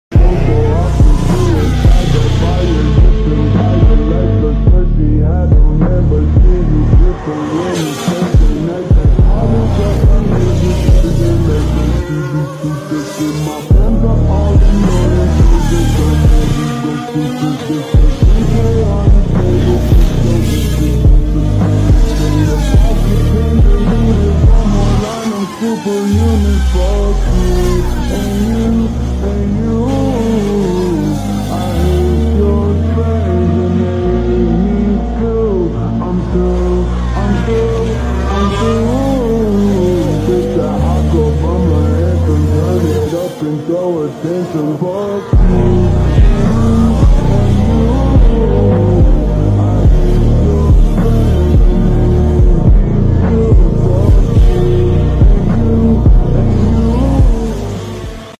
Sit back and enjoy this amazing sound of the W11